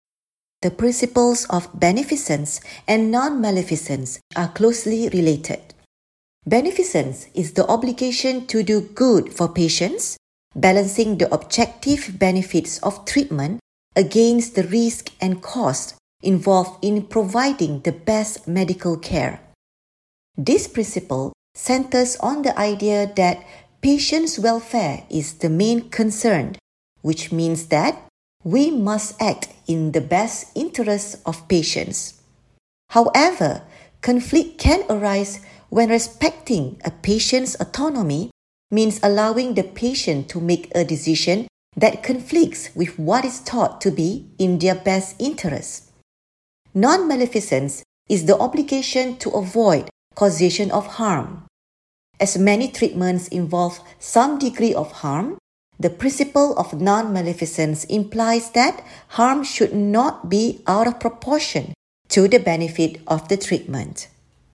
Narration audio (MP3) Contents Home What is Medical Ethics?